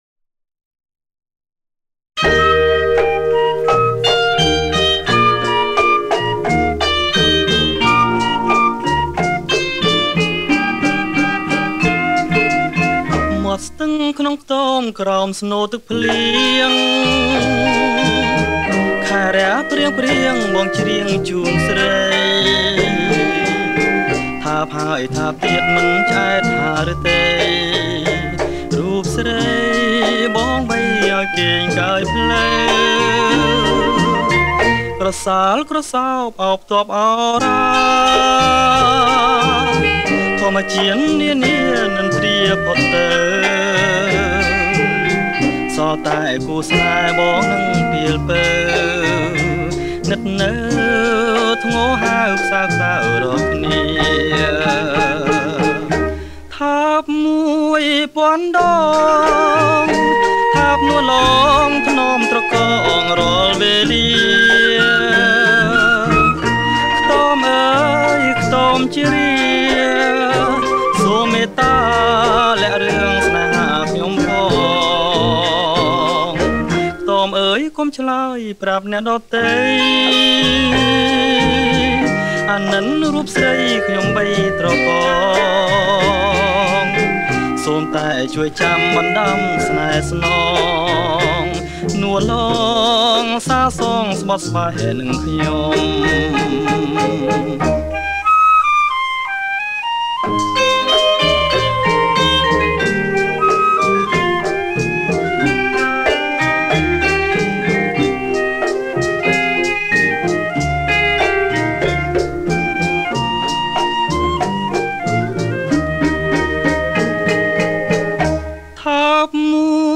• ប្រគំជាចង្វាក់ Rumba lent